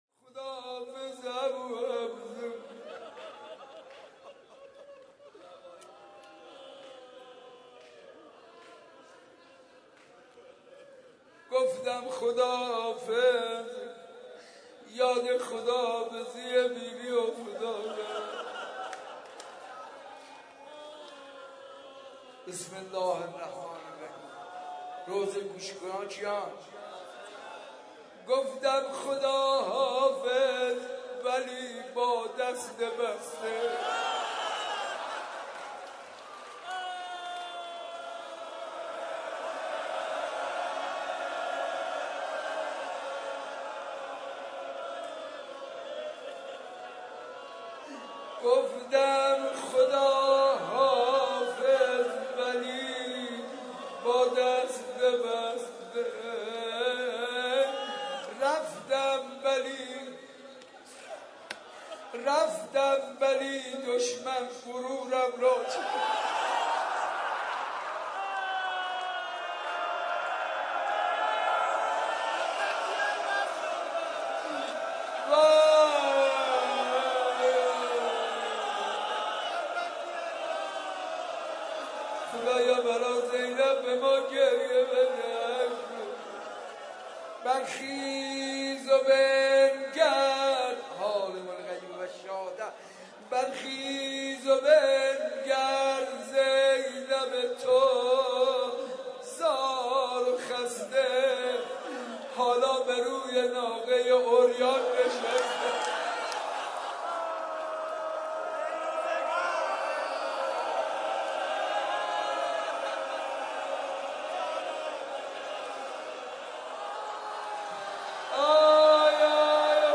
مناجات و دعای ابوحمزه
روضه حضرت زینب (س) و سینه زنی